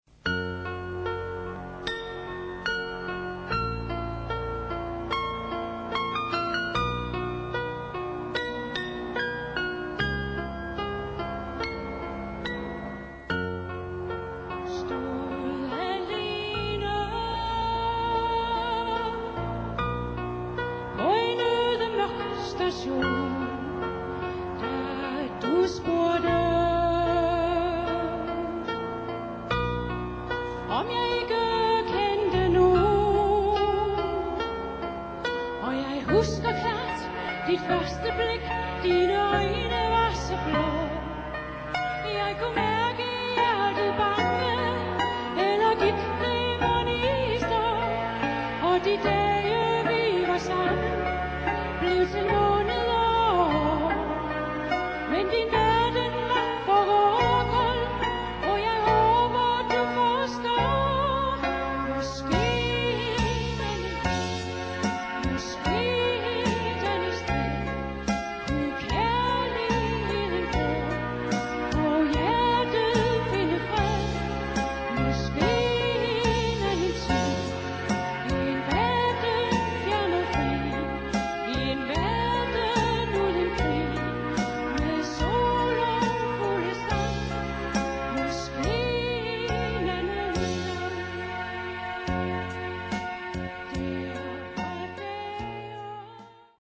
"live on stage!"